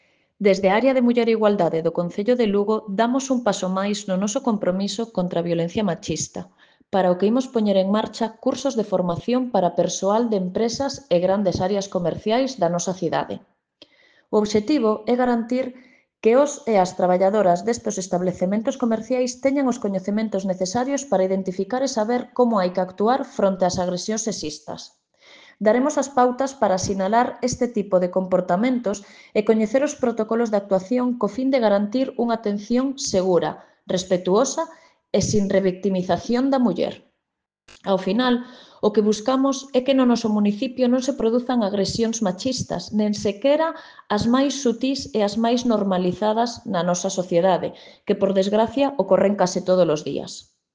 La concejala de Mujer e Igualdad del Ayuntamiento de Lugo, Ángeles Novo, sobre los puntos seguros | Descargar mp3